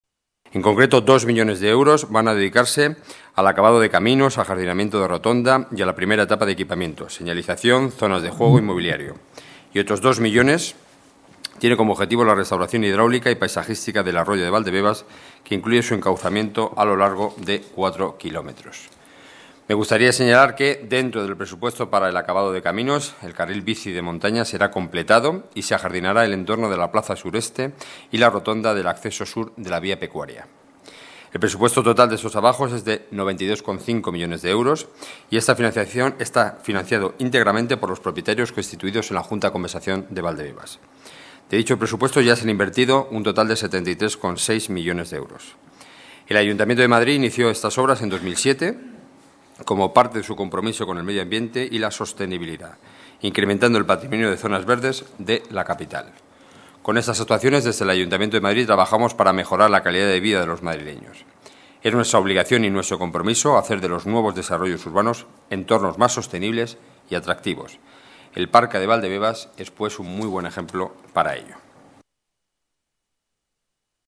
Nueva ventana:Declaraciones de Enrique Núñez, portavoz del Gobierno: Parque Valdebebas